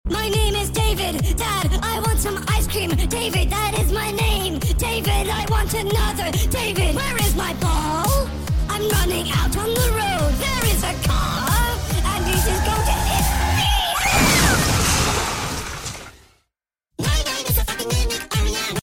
voice impression